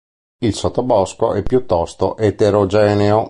sot‧to‧bò‧sco
/sot.toˈbɔs.ko/